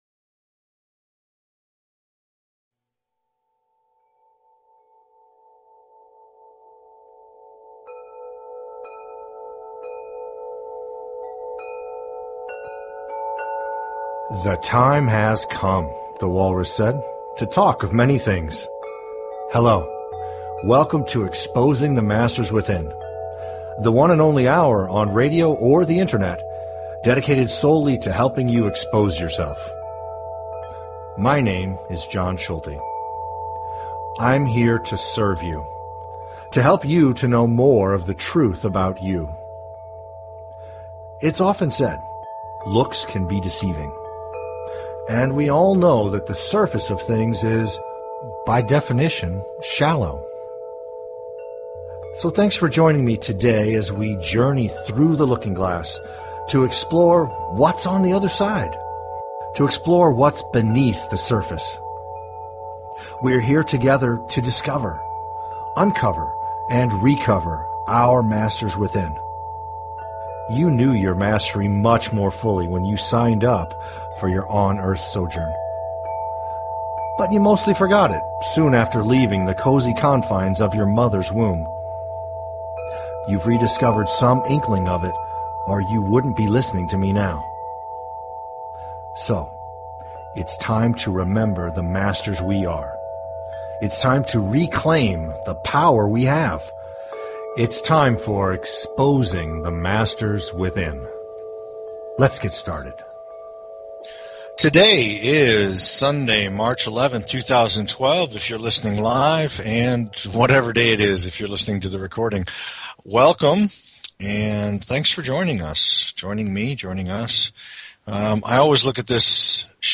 Talk Show Episode, Audio Podcast, Exposing_the_Masters_Within and Courtesy of BBS Radio on , show guests , about , categorized as